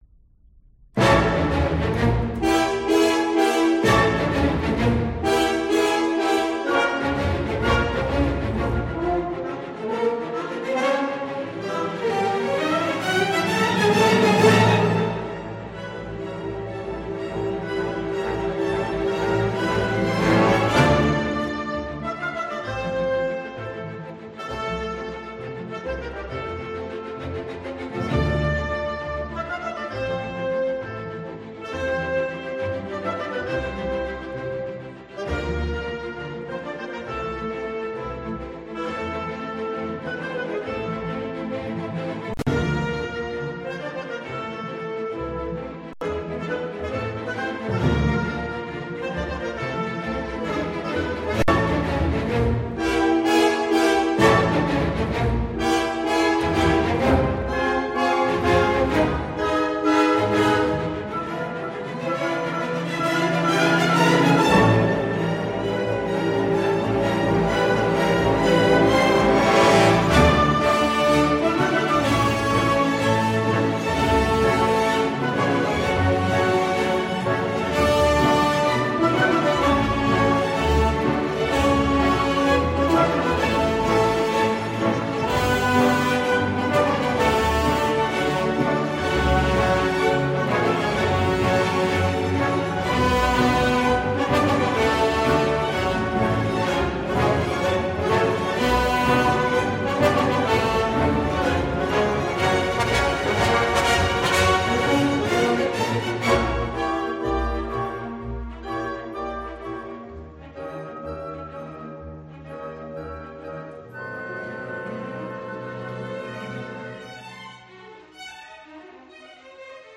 Nous allons retrouver l’orchestre de Francfort, cette fois dirigé par un chef d’origine grecque :
Voici celui de la quatrième, Allegro feroce :